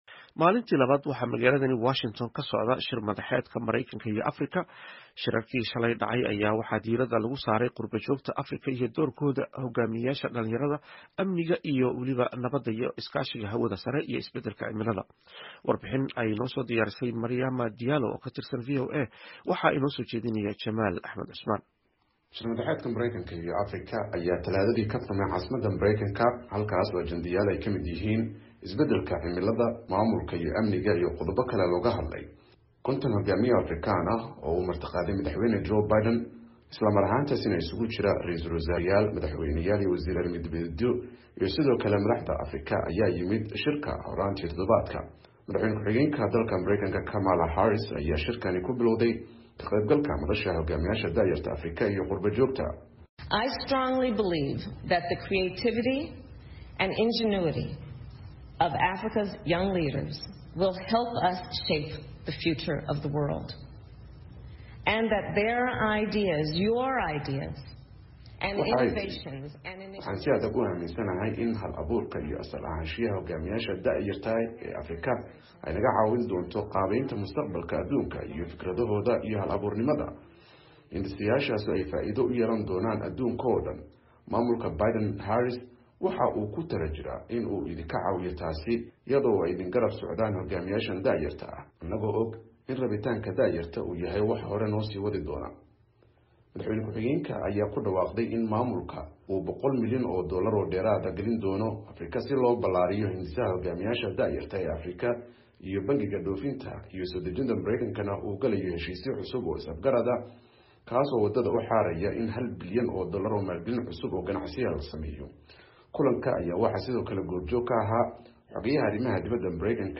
Warbixin ku saabsan maalintii labaad ee shir madaxeedka Mareykanka iyo Afrika